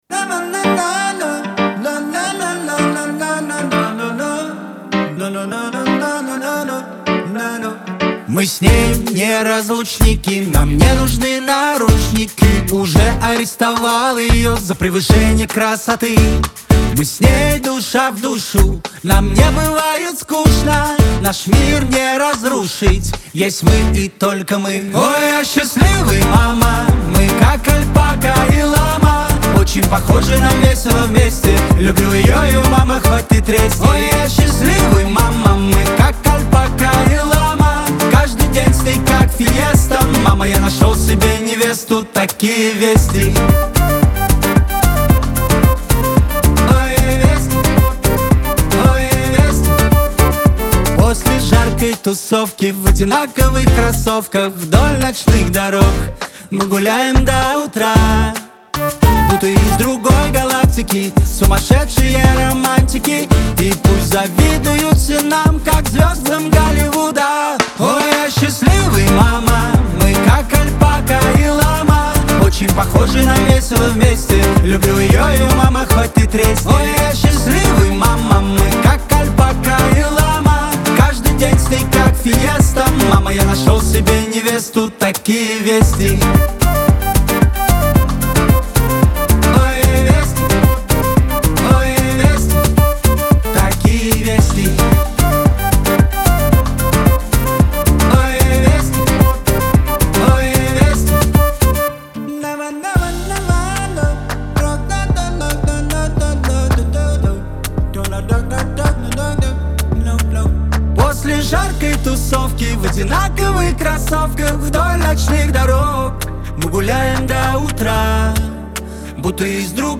Лирика
Веселая музыка